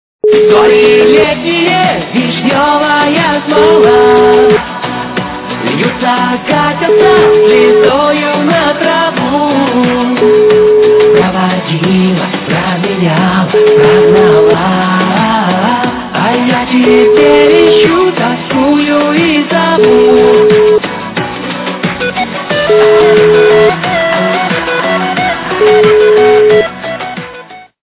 русская эстрада
качество понижено и присутствуют гудки.